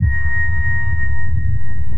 sonarPingSuitClose1.ogg